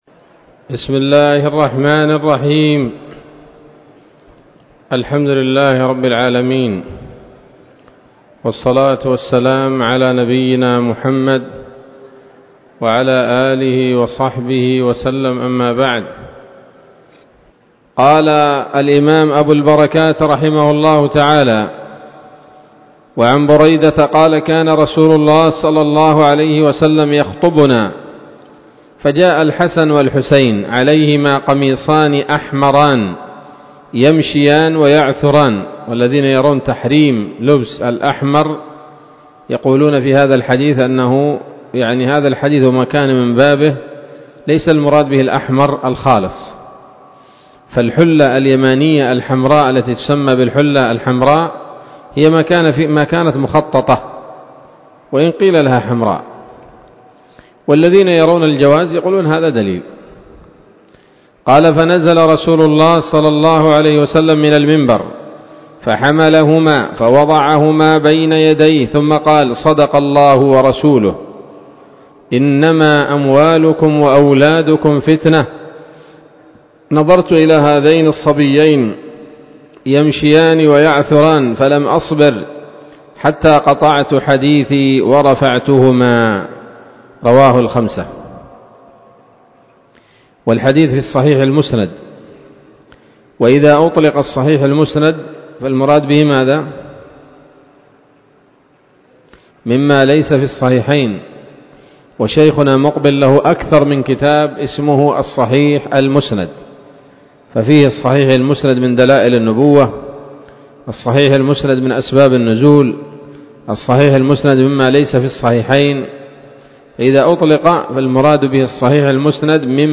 الدرس الثاني والثلاثون من ‌‌‌‌أَبْوَاب الجمعة من نيل الأوطار